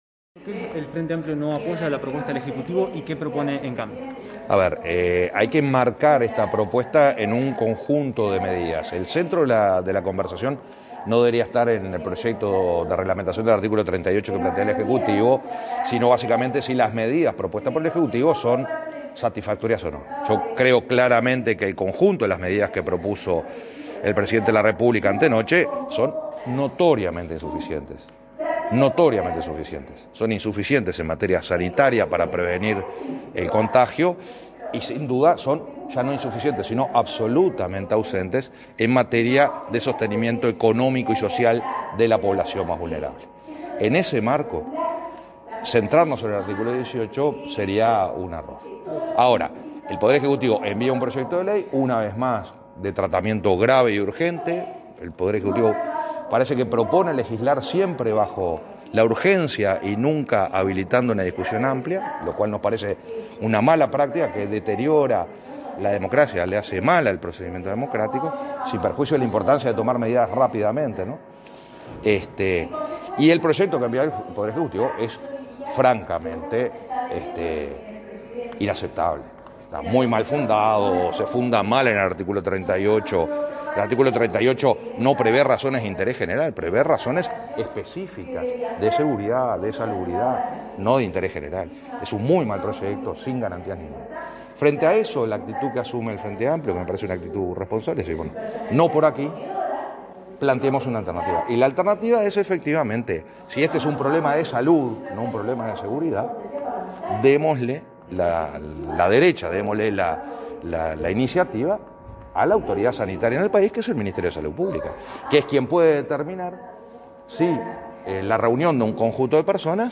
Adjuntamos audio con declaraciones completas